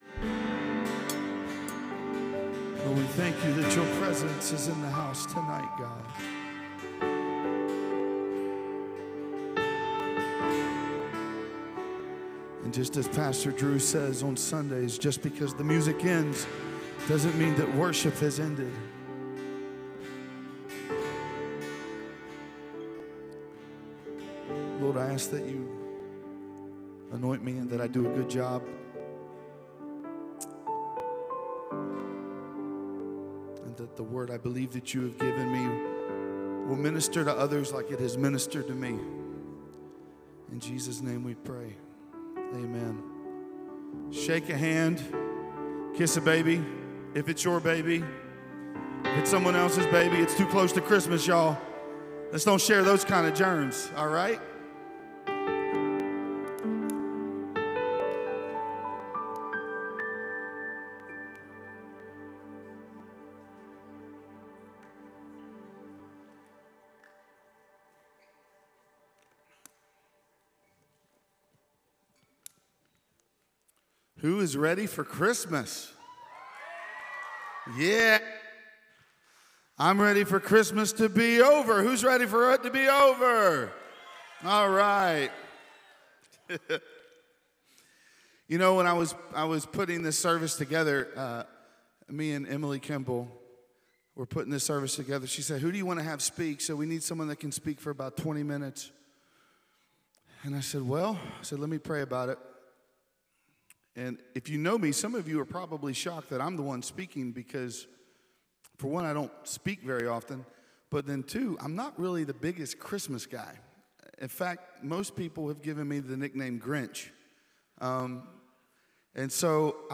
Christmas Eve Eve Service | 12.23.24